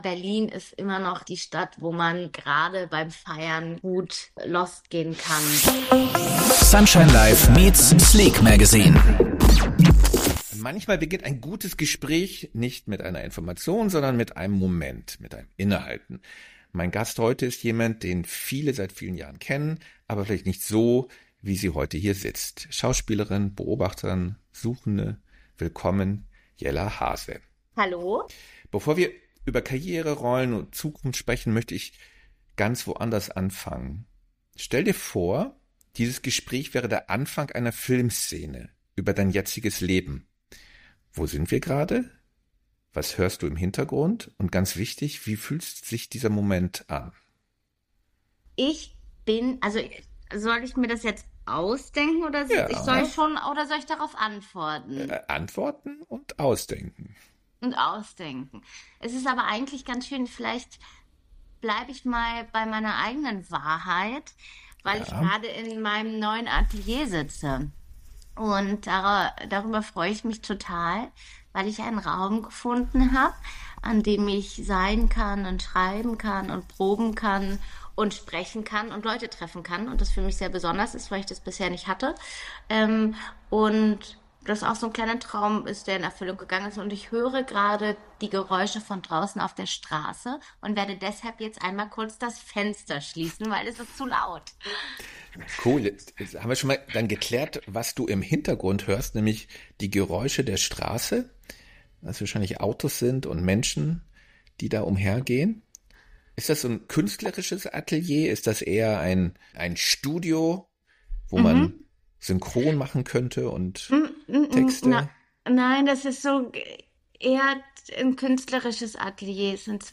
Ein persönliches Gespräch über kreative Arbeit, Sichtbarkeit und die Frage, wie Identität entsteht, wenn man im Fokus der Öffentlichkeit steht.